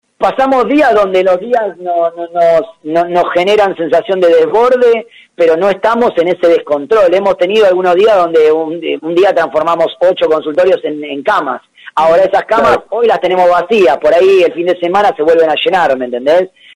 Nota completa de Radio Universidad FM 89.1